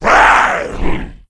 c_saurok_hit2.wav